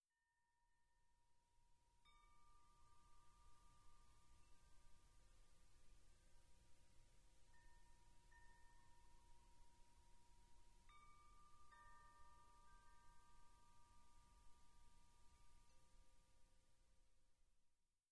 ModChimes produce beautiful, distinct, bell-like tones
F4 G4 A4 C5 D5
F4-G4-A4-C5-D5.mp3